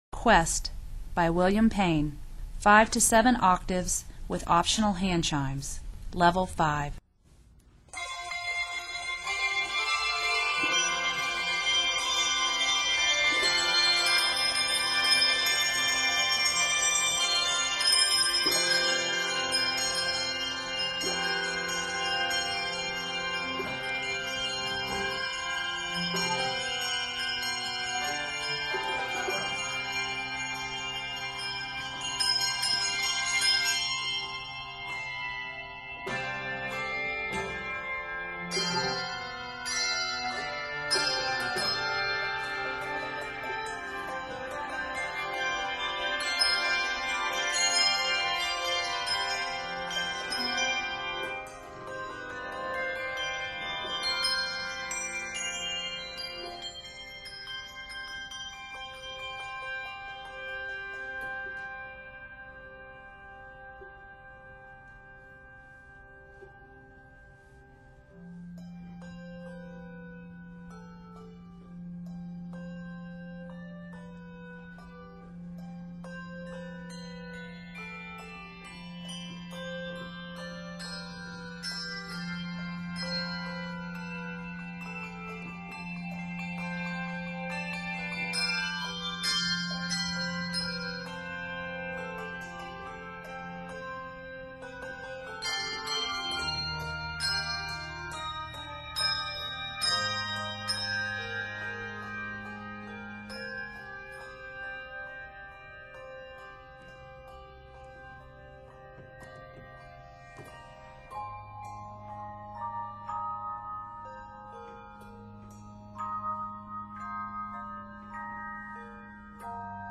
Rich in musical textures
is scored in several keys